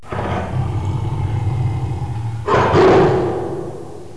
Littleton Zoo Tigers
Click here to hear the tigers roar!!
tiger1.wav